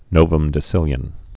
(nōvəm-dĭ-sĭlyən)